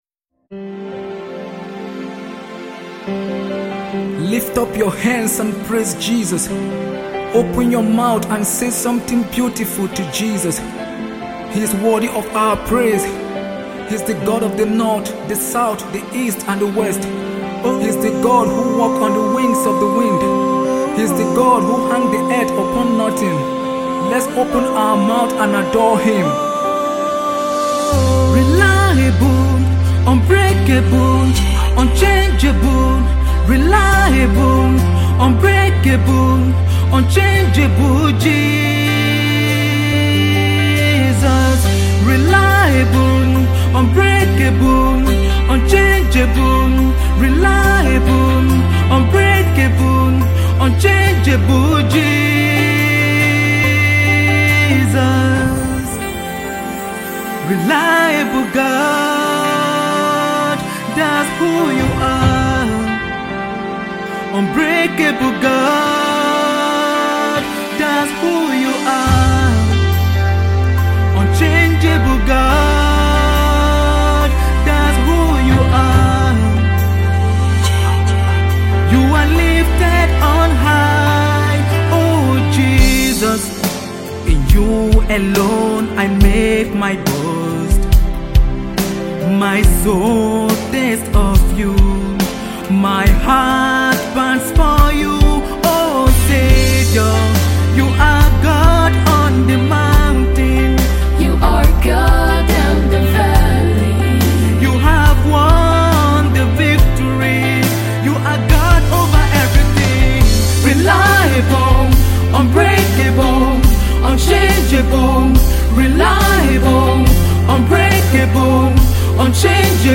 Sensational Nigerian Gospel artist
a song of praise and worship to God our king.